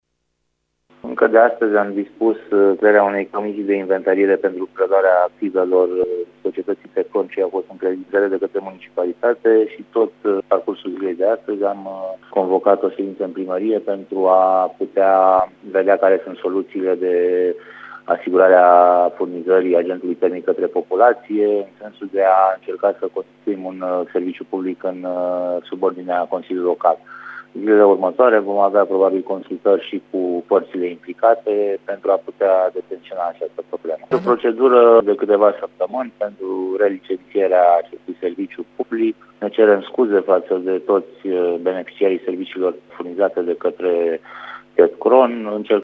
Municipalitatea a luat deja primele măsuri pentru a rezolva această problemă a declarat pentru Radio Tîrgu-Mureș, viceprimarul municipiului Brașov, Ciprian Bucur: